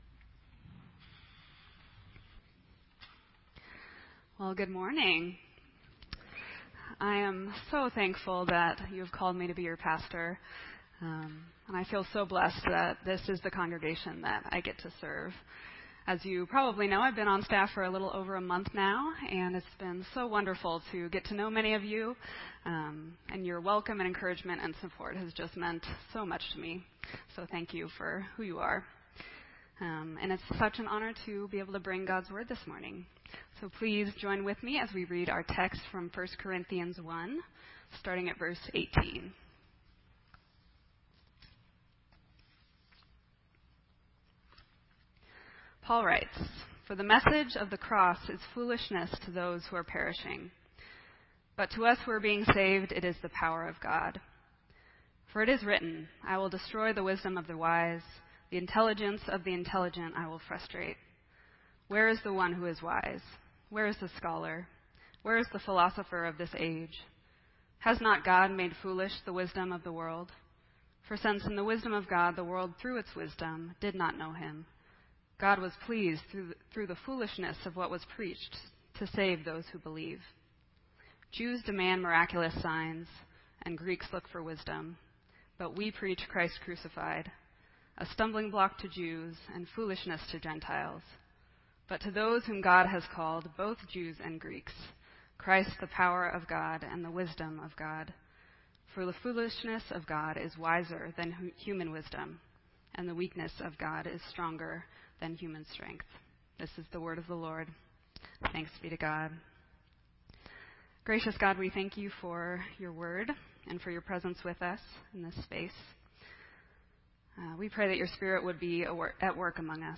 This entry was posted in Sermon Audio on October 17